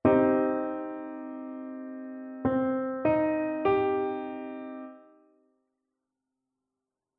Aufbau des Moll-Dreiklangs
Beim Moll-Dreiklang (zweiter von links) sind die Terzen vertauscht
DreiklangMollGrundstellung.mp3